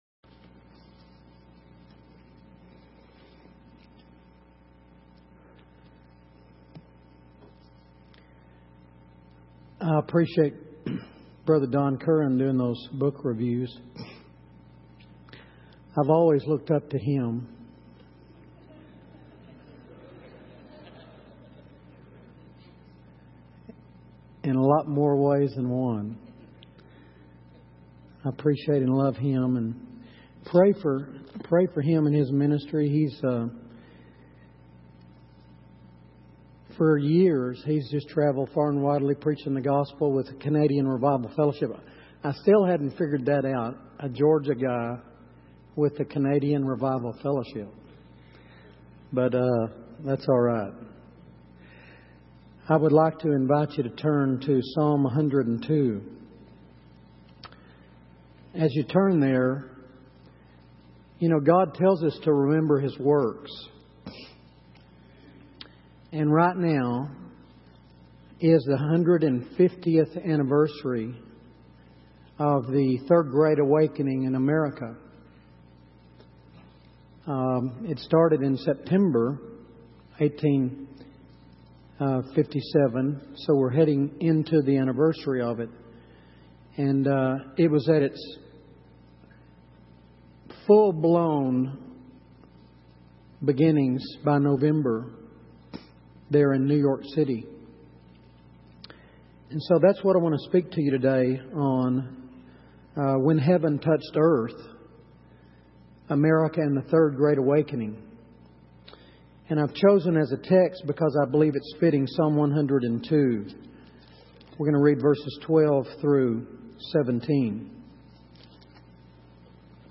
In this sermon, a young actor in New York City shares his testimony of how he embraced Jesus Christ through attending prayer meetings for two months.